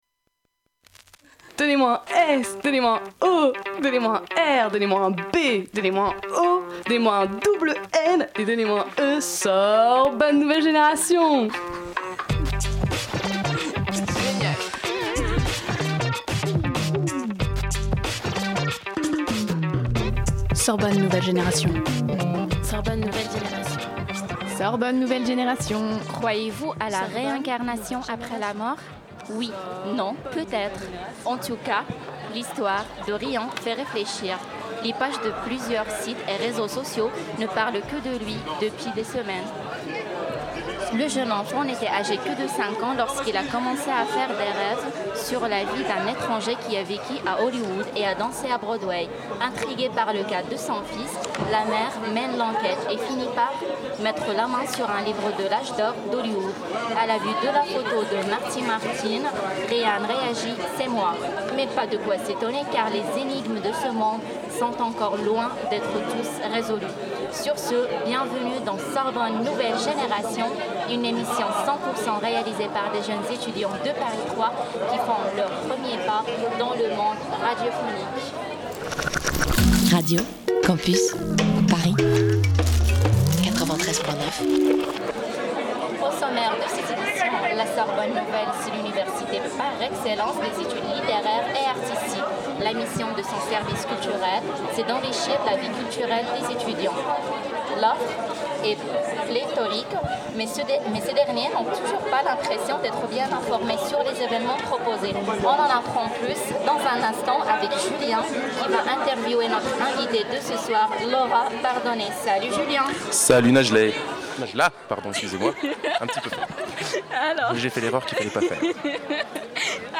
Pour le second semestre de son Tour des Facs 2014-2015, Radio Campus Paris lance une deuxième session de formation radio à La Sorbonne Nouvelle Paris 3 dans le cadre d’un partenariat avec Le Service d’action culturelle. Après un cycle de 13 ateliers d'apprentissage intensifs des techniques radiophoniques, les 5 étudiants participants ont enregistré une émission de radio en plein air et en public sur le parvis de l'Université Paris 3 Sorbonne Nouvelle.